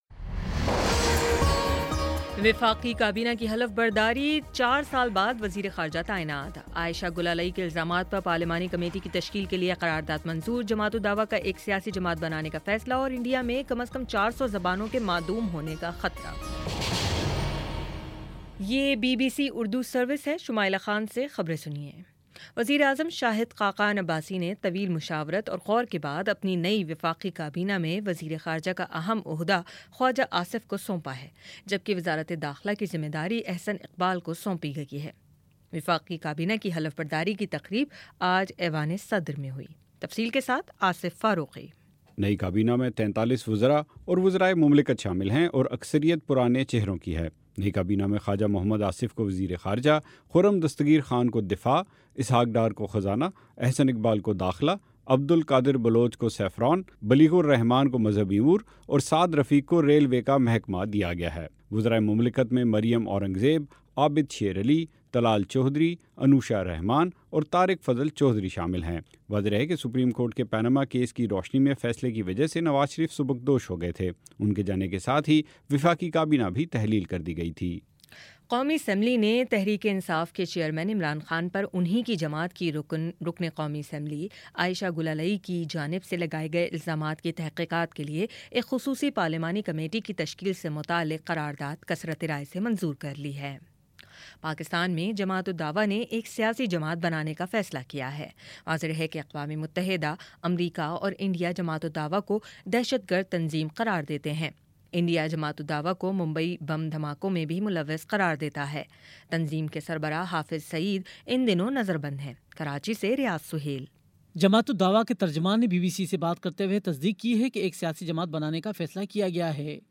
اگست 04 : شام سات بجے کا نیوز بُلیٹن